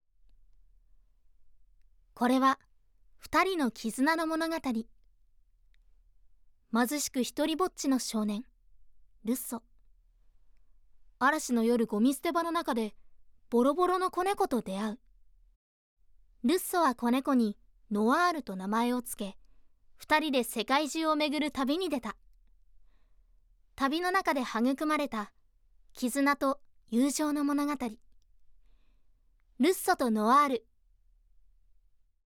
ボイスサンプル
ナレーション（映画予告）